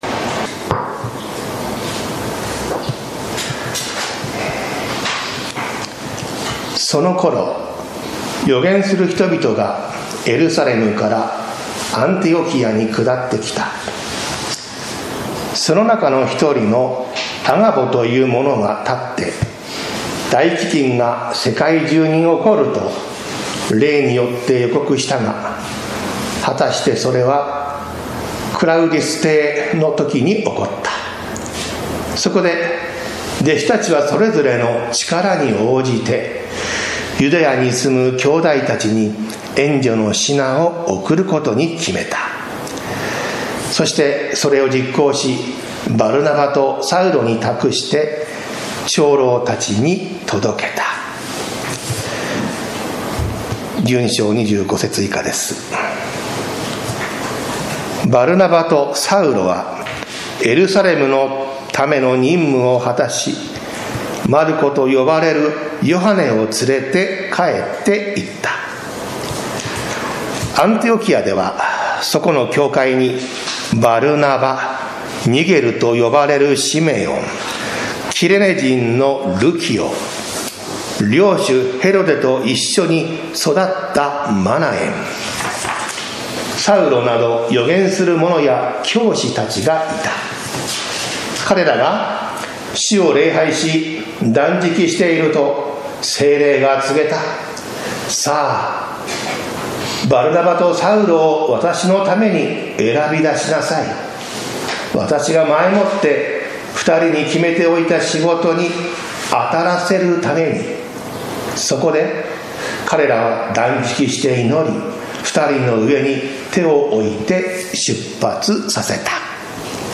教会の成長 宇都宮教会 礼拝説教